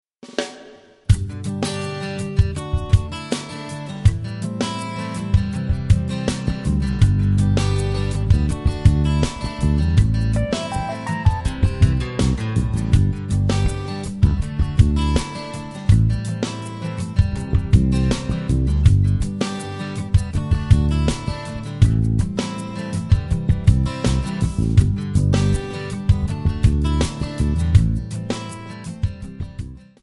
Backing track Karaoke
Pop, 1990s